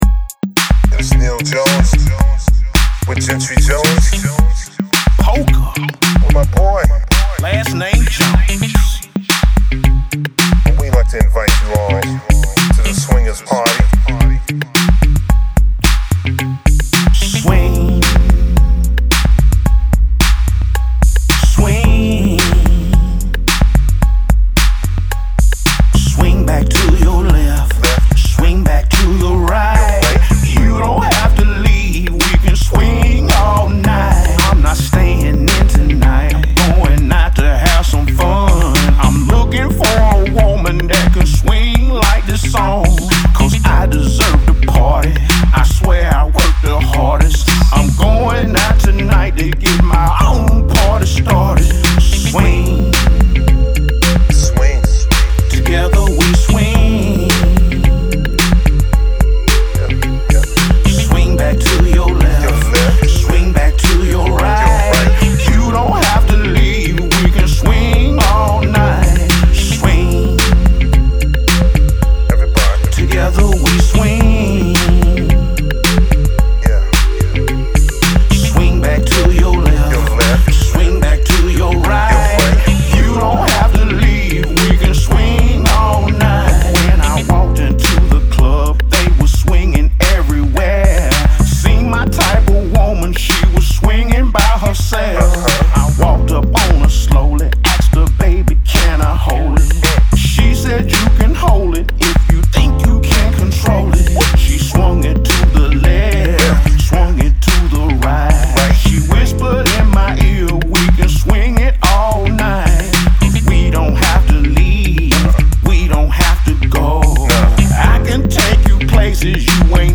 Soul